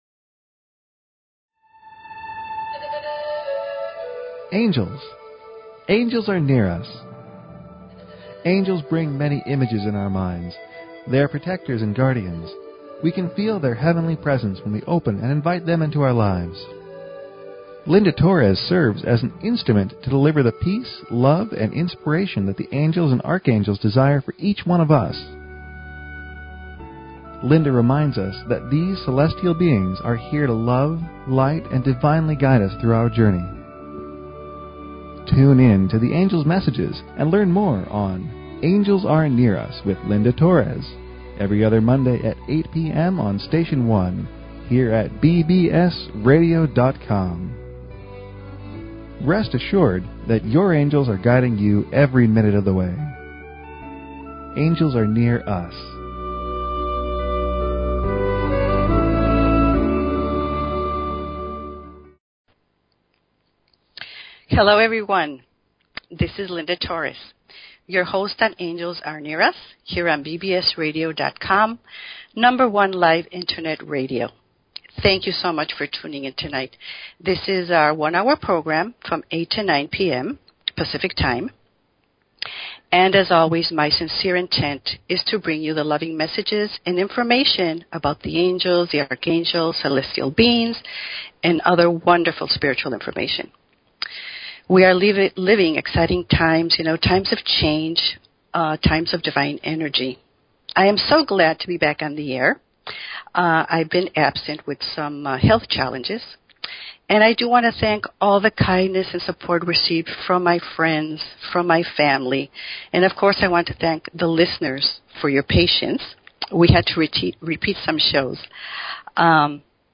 Talk Show Episode, Audio Podcast, Angels_Are_Near_Us and Courtesy of BBS Radio on , show guests , about , categorized as
The last 30 minutes of the show the phone lines will be open for questions and Angel readings.